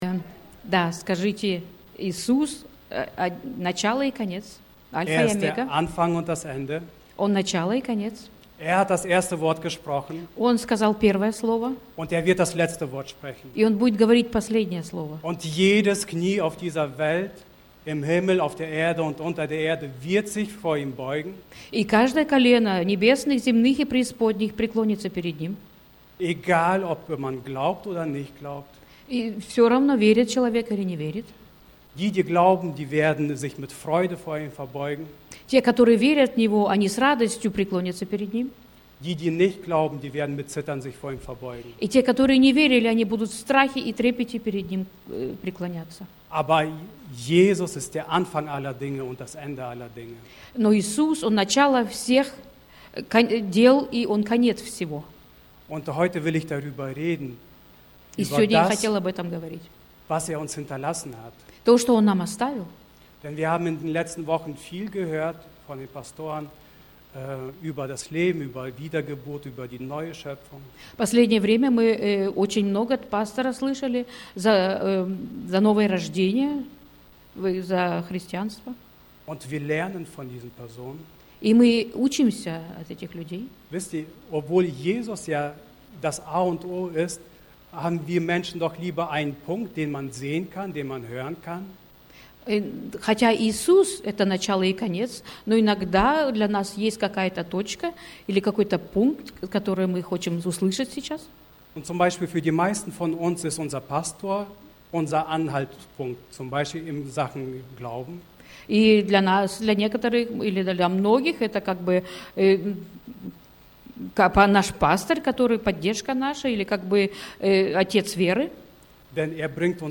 Predigten – Freie Evangeliums Christengemeinde Löningen